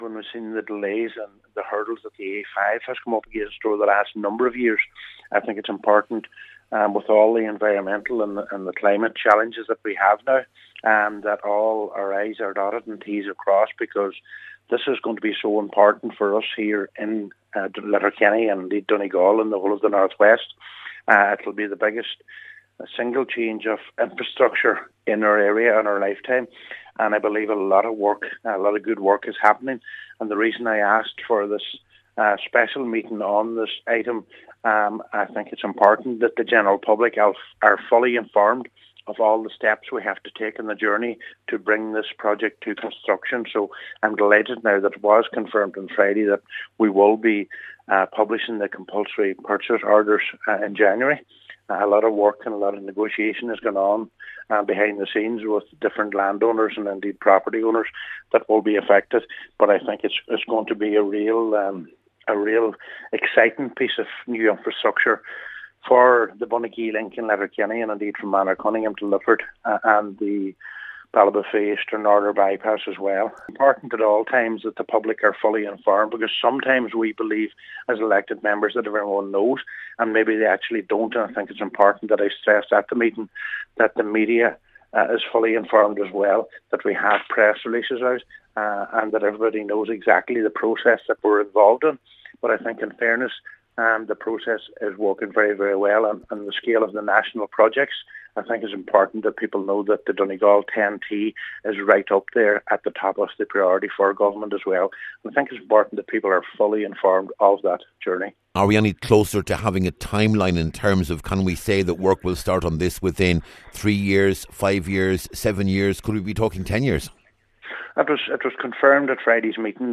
Cllr Ciaran Brogan, Mayor of the Letterkenny Milford Municipal District says it’s vital that all i’s are dotted and t’s are crossed to avoid the sort of issues being experienced with the A5 north of the border…………